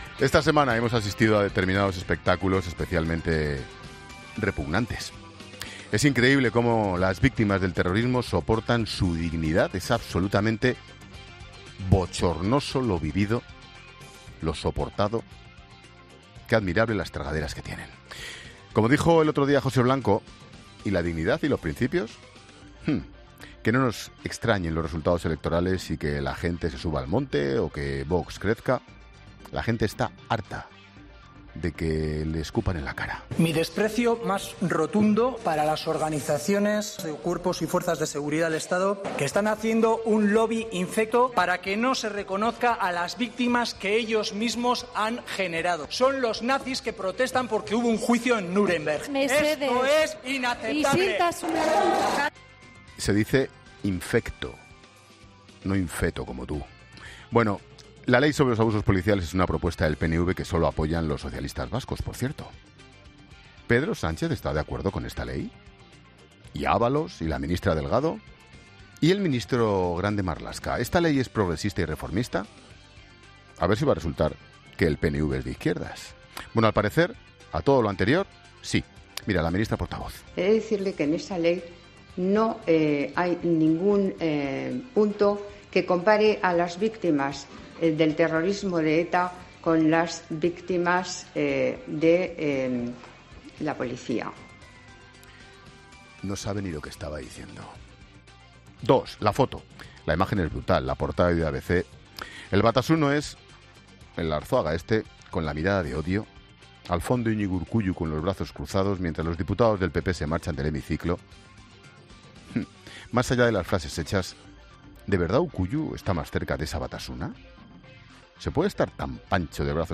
Monólogo de Expósito
Ángel Expósito analiza la actualidad en 'La Linterna' de COPE